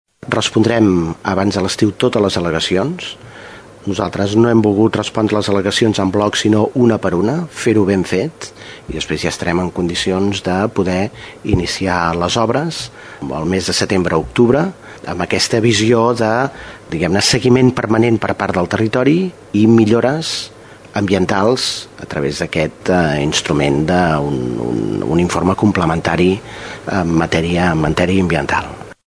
Aquest és el compromís de la Generalitat, que està acabant d’estudiar les al·legacions dels alcaldes de la zona (Tordera, Blanes, Lloret i Tossa de Mar), que reclamen que aquesta gran obra tingui el mínim impacte ambiental. En una entrevista a l’ACN, el conseller de Territori i Sostenibilitat, Josep Rull, ha avançat que el seu departament respondrà a les al·legacions abans que s’acabi l’estiu, i que després d’això ja podran començar les obres.